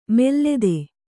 ♪ mellede